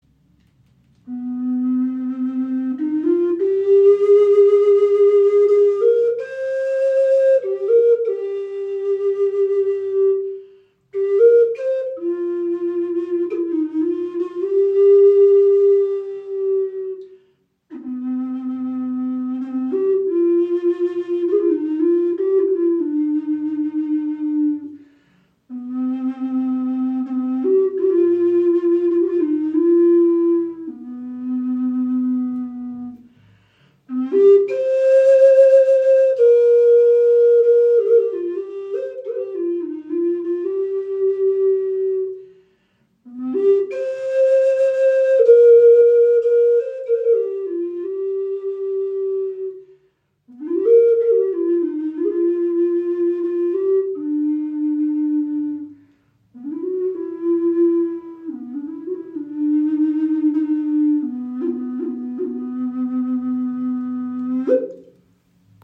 Handgefertigte 5 Loch Okarina aus Teakholz | Pentatonisch C4 Moll 432 Hz • Raven Spirit
Okarina aus einem Aststück | C4 in 432 Hz | Pentatonische Stimmung | ca. 19 cm
Klein, handlich, klangvoll – eine Okarina mit Seele
Handgefertigte 5 Loch Okarina aus Teakholz – pentatonische C4 Moll Stimmung in 432 Hz, warmer klarer Klang, jedes Stück ein Unikat.
Trotz ihrer handlichen Grösse erzeugt sie einen angenehm tiefen und warmen Klang – fast ebenbürtig zur nordamerikanischen Gebetsflöte.